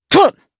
Lucias-Vox_Attack3_jp_b.wav